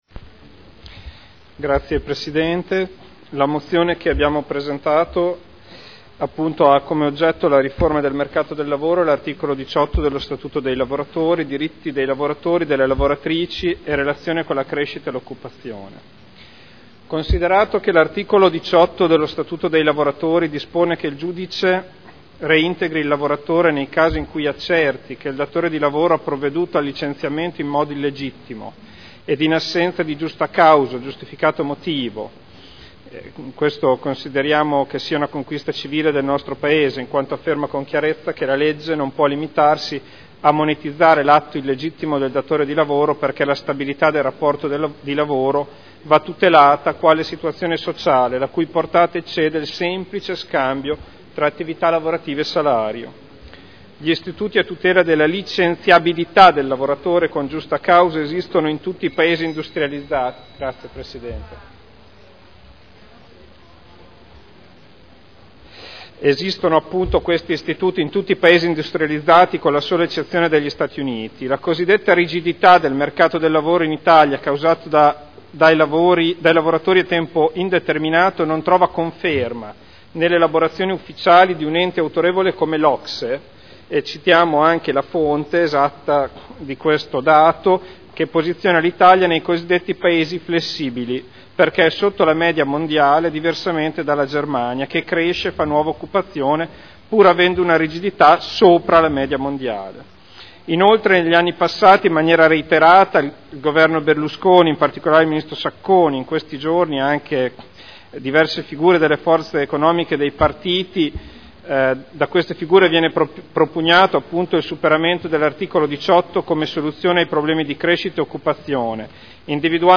Federico Ricci — Sito Audio Consiglio Comunale
Seduta del 27 febbraio. Mozione presentata dai consiglieri Ricci (Sinistra per Modena) e Trande (P.D.) avente per oggetto: “Riforma del “mercato del lavoro” e Articolo 18 dello Statuto dei Lavoratori: diritti dei lavoratori, delle lavoratrici e relazione con la crescita e occupazione”